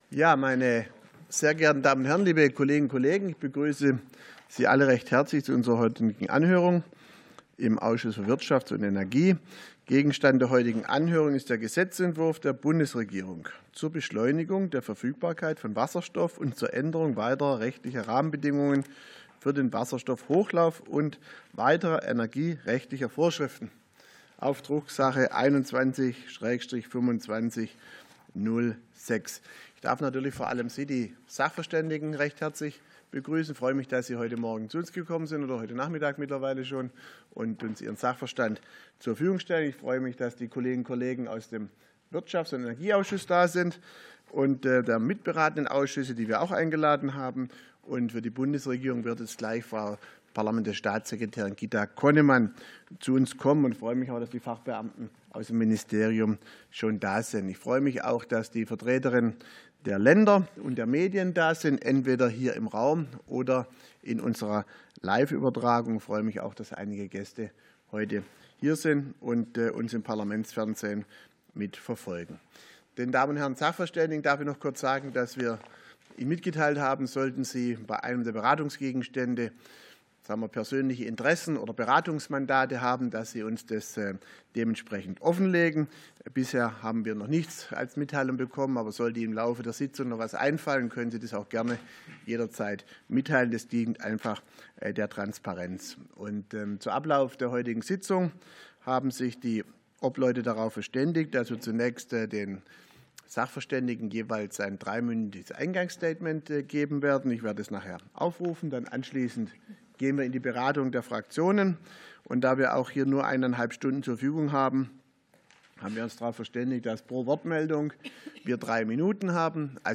Anhörung des Ausschusses für Wirtschaft und Energie